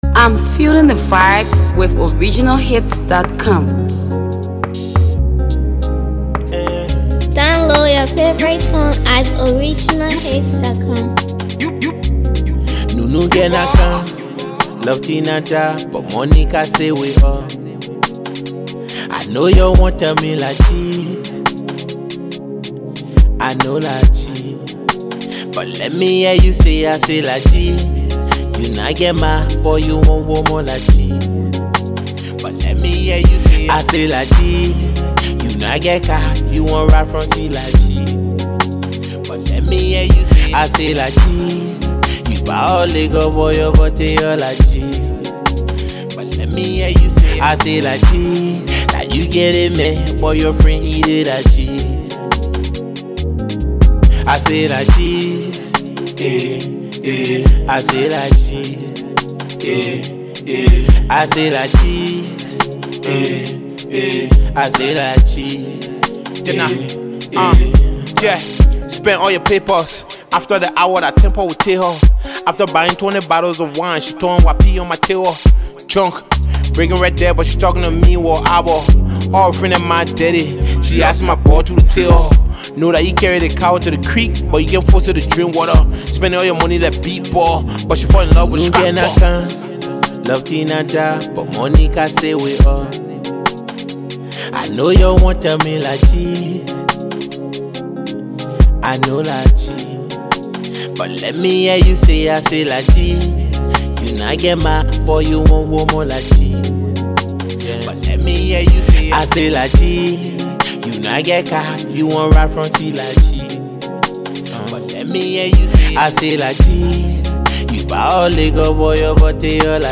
fresh playlist banger
guaranteed to get listeners on their feet.